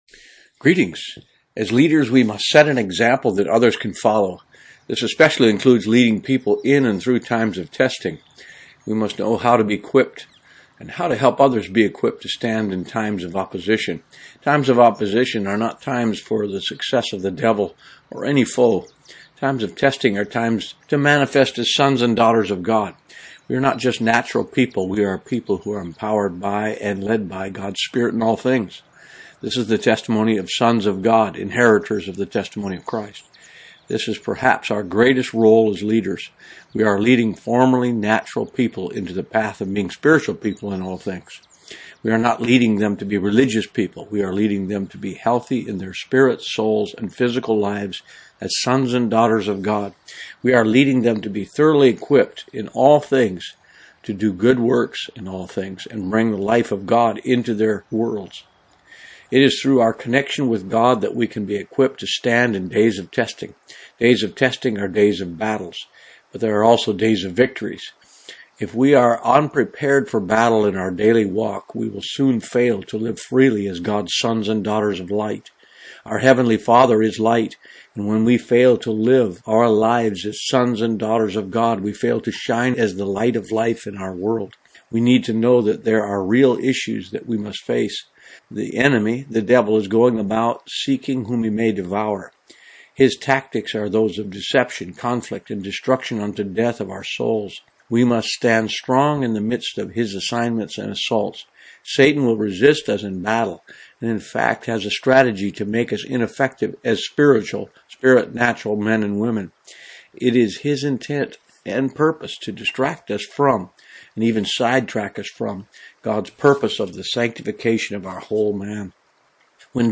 Blog In Audio: